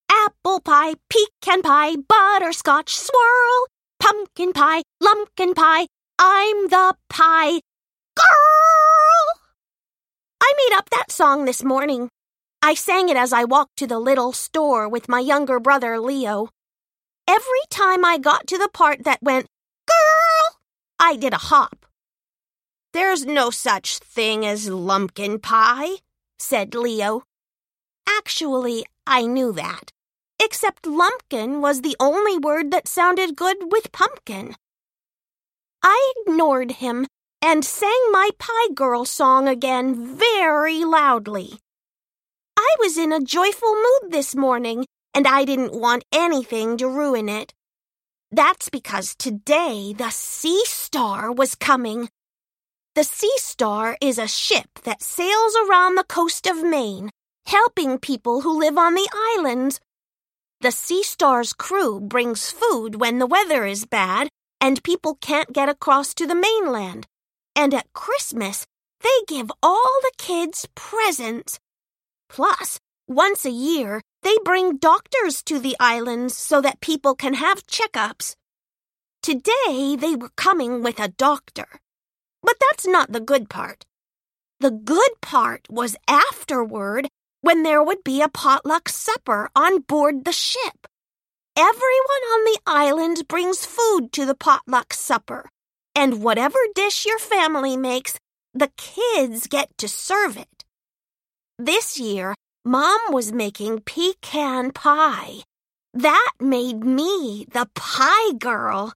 Middle Grade Audiobooks